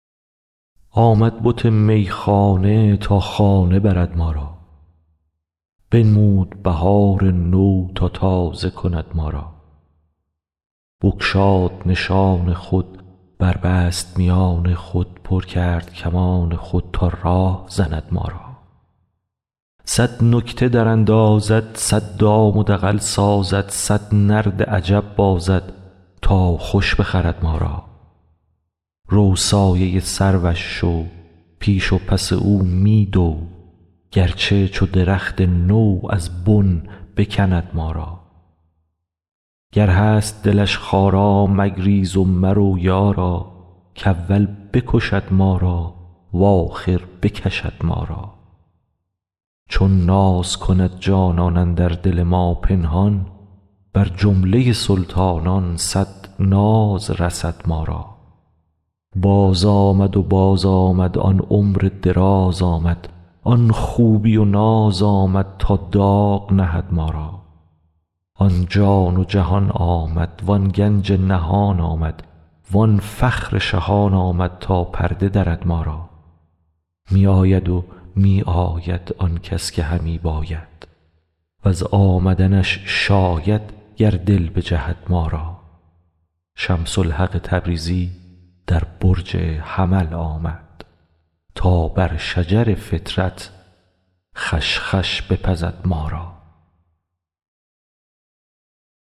مولانا دیوان شمس » غزلیات غزل شمارهٔ ۷۳ به خوانش